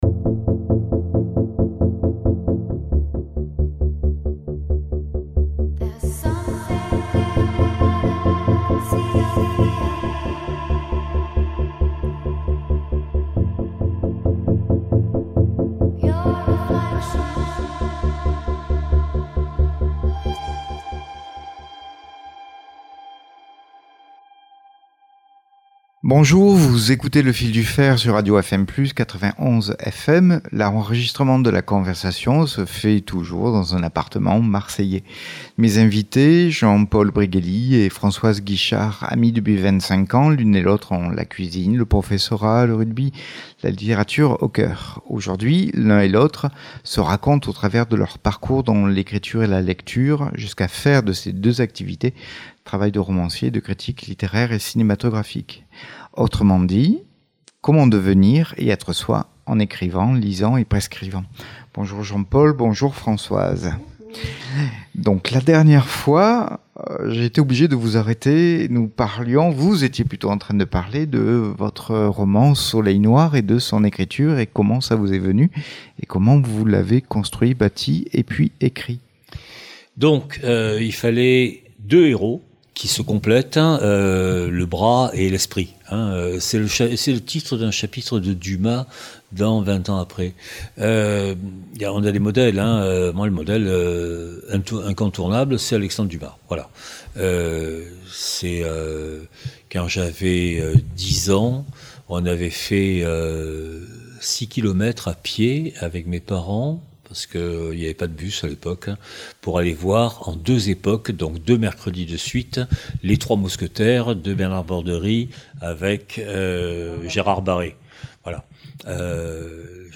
EPISODE 5/5: Bonjour, vous écoutez Le Fil du faire sur Radio FM Plus – 91 FM. L’enregistrement de la conversation se fait dans un appartement marseillais.